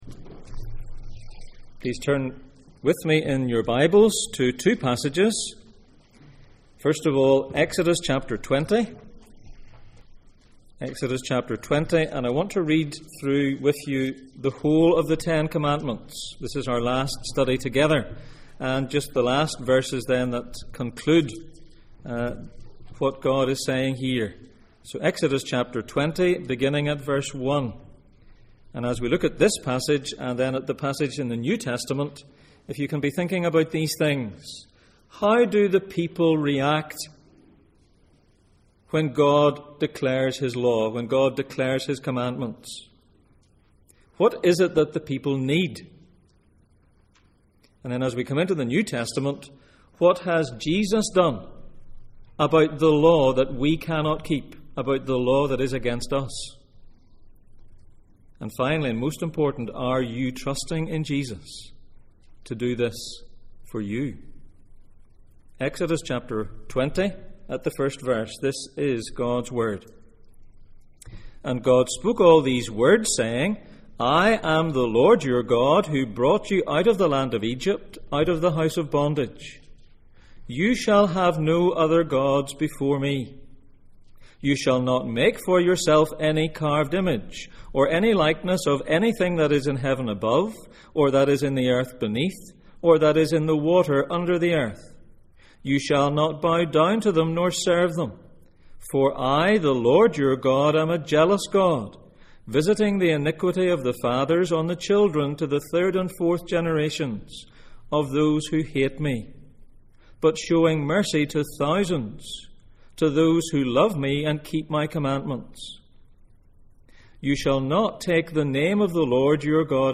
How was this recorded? Passage: Exodus 20:1-21, Romans 8:1-4, Exodus 24:7-8 Service Type: Sunday Morning